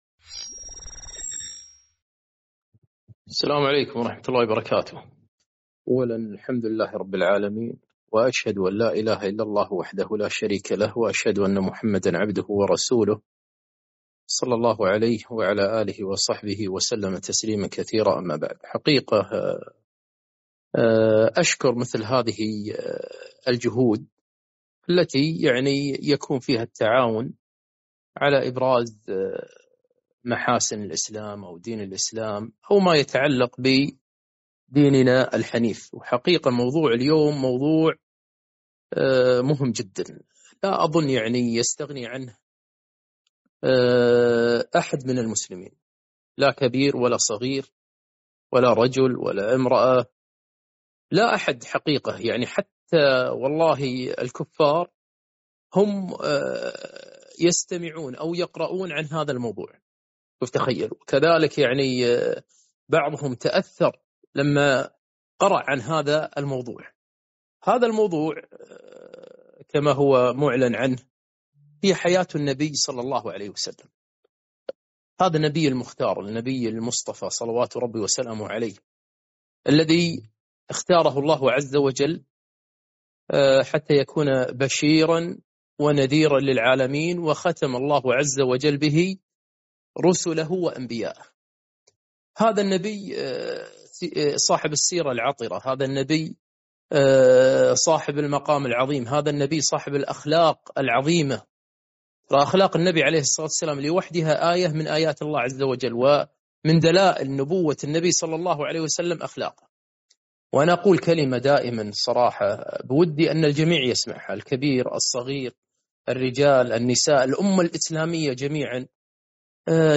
محاضرة - حياة الرسول صلى الله عليه وسلم